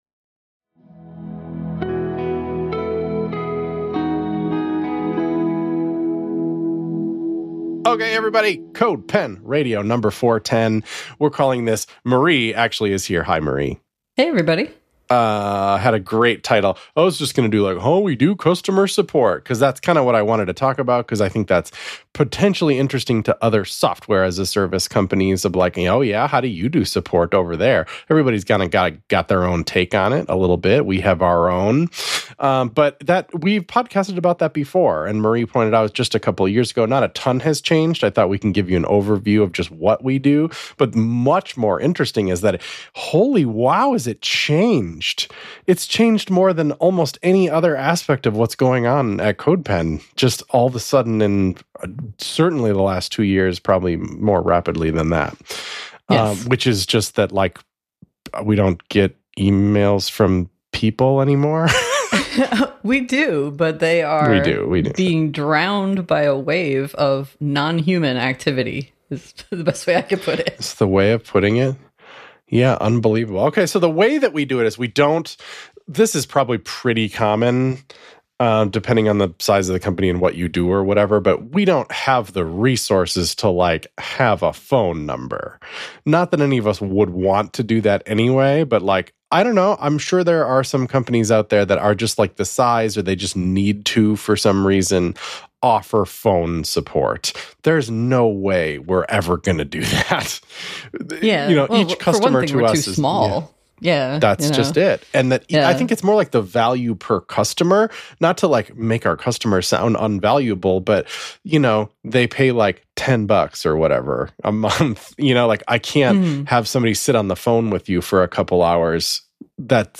The CodePen team talk about the ins and outs of running a web software business.